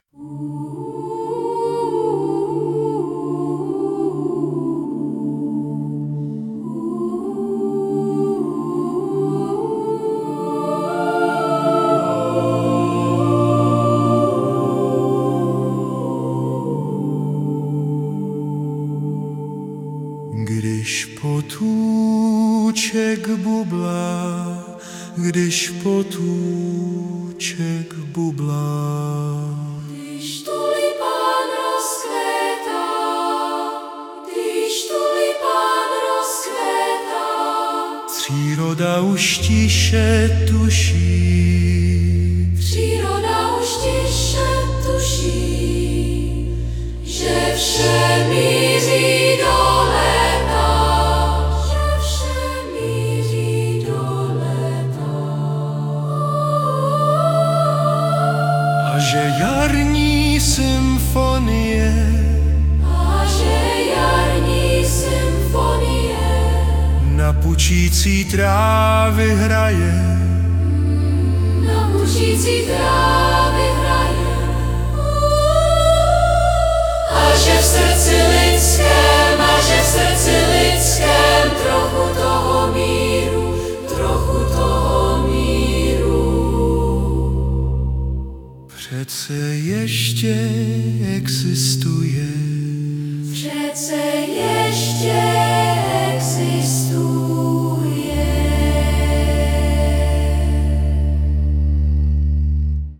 hudba a zpěv: AI (by SUNO)
text o lidskosti, který pak zazpívá robot- nádhera:))